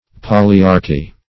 Search Result for " polyarchy" : The Collaborative International Dictionary of English v.0.48: Polyarchy \Pol"y*ar`chy\, n. [Poly- + -archy: cf. F. polyarchie.